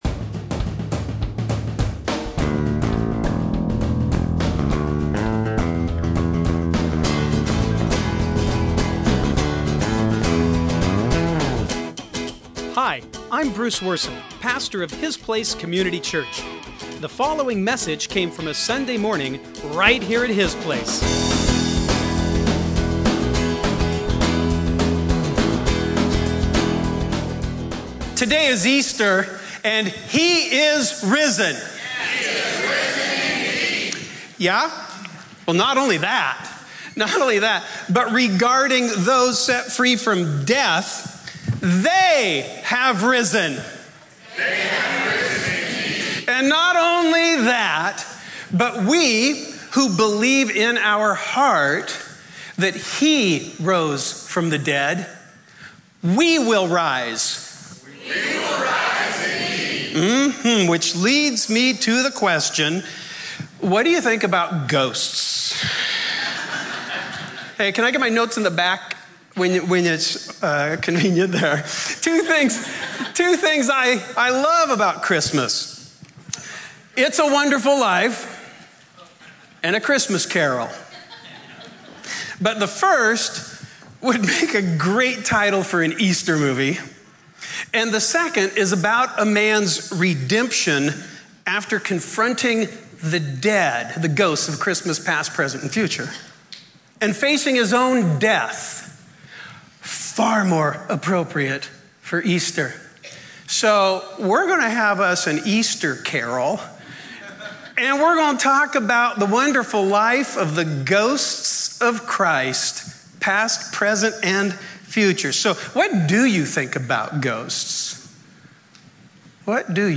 Easter Sermon